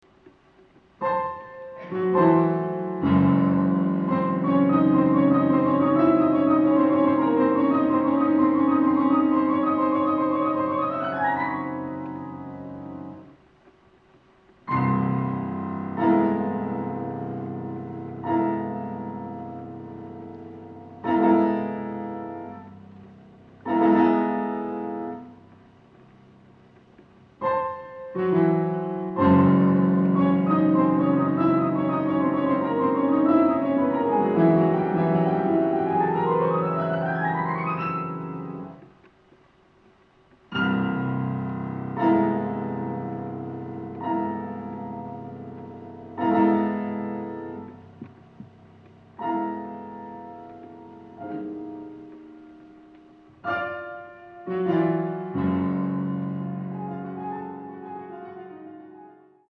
Concerto for Piano and Orchestra